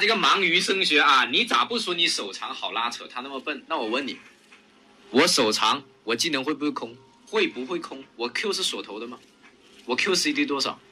Autentisk Voiceover för Influencermarknadsföring
Fängsla din publik med en energisk, relaterbar AI-röst designad för sociala medietrender, varumärkesberättelser och viralt engagemang.
Hög Energi